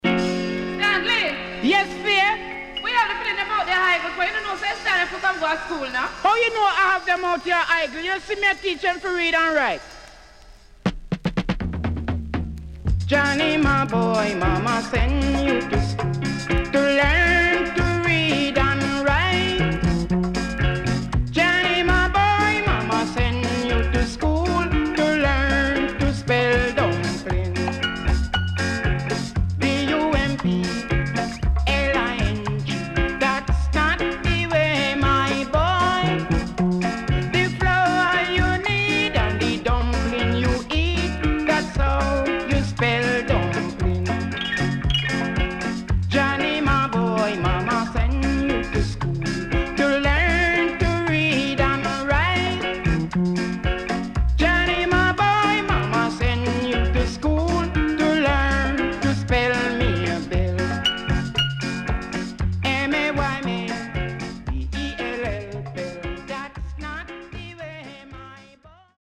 77年 Nice Calypso.Good Condition
SIDE A:盤質は良好です。